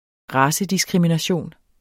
Udtale [ ˈʁɑːsə- ]